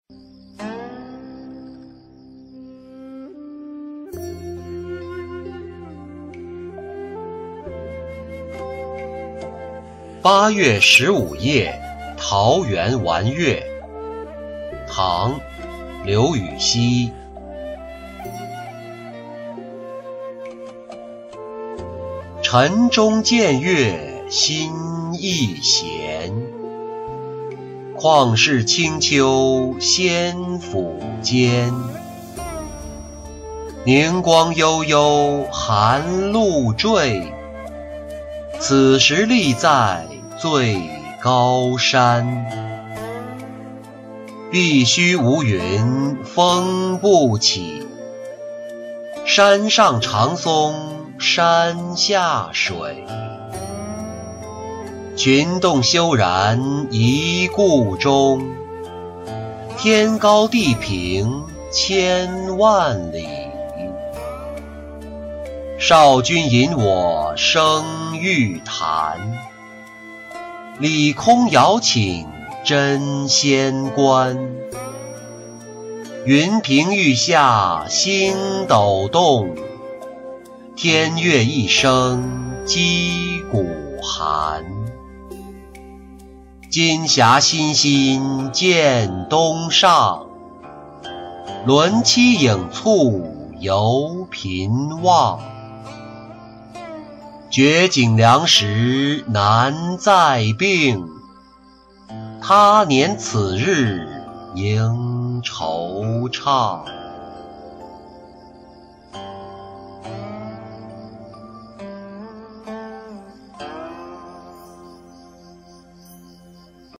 八月十五夜桃源玩月-音频朗读